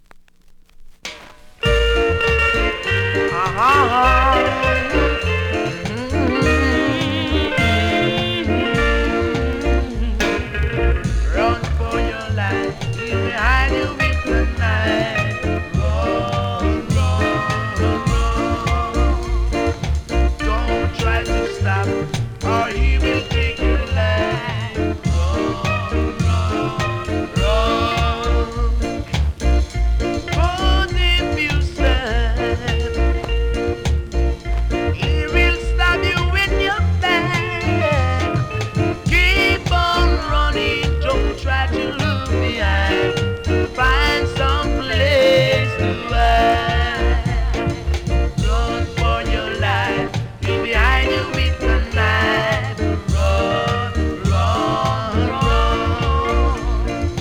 うすキズそこそこありますがノイズは数発程度。